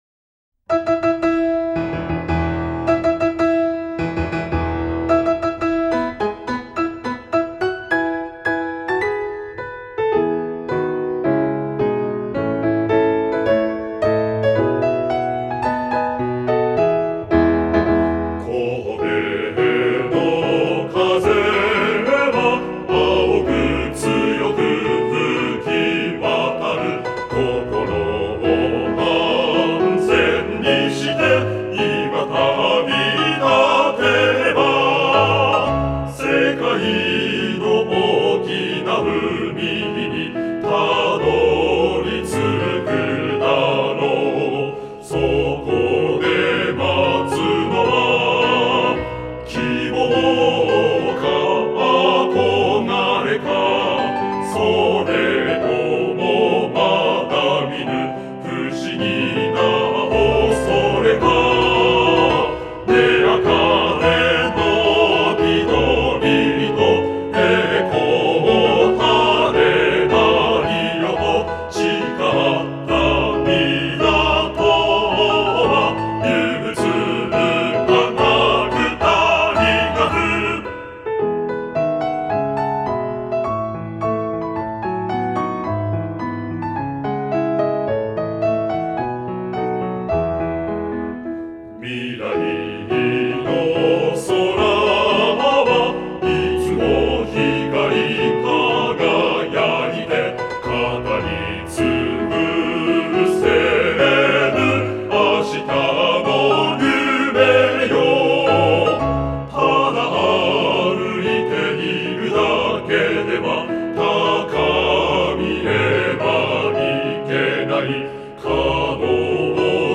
合唱バージョン